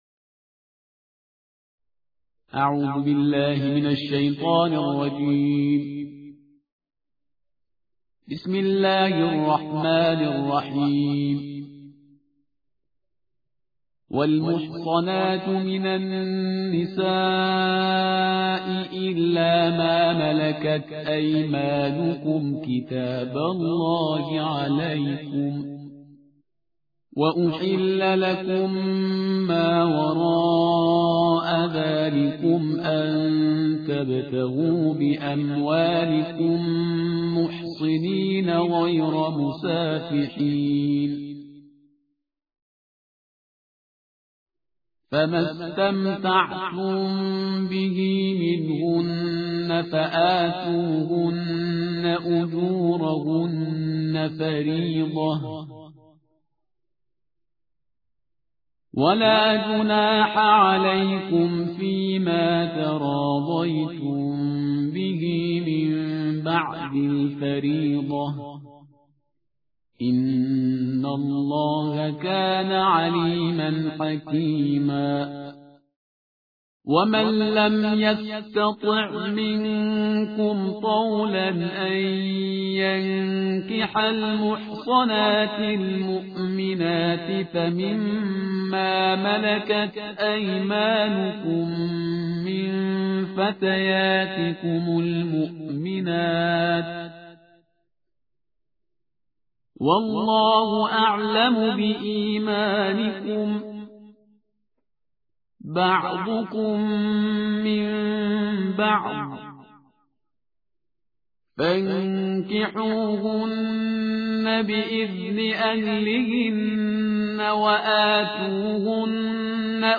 ترتیل جزء پنج قرآن کریم